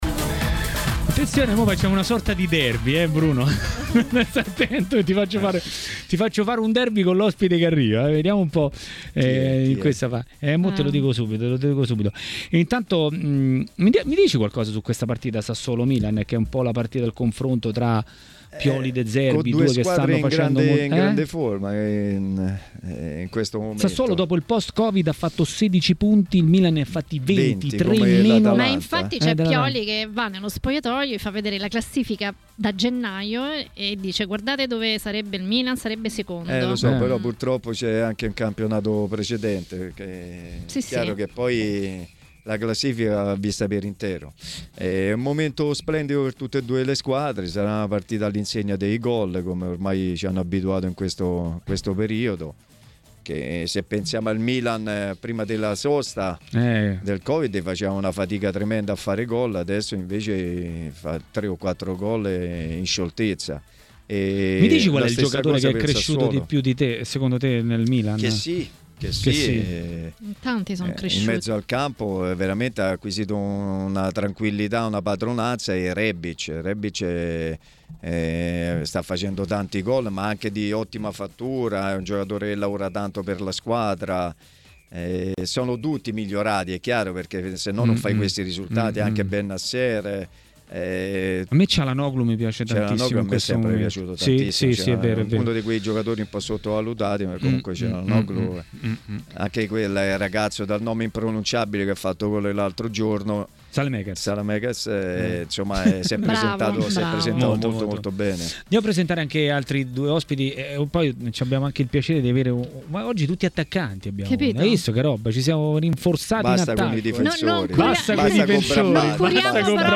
A dire la sua sugli argomenti di giornata a TMW Radio, durante Maracanà, è stato l'ex attaccante Antonio Floro Flores.
Le Interviste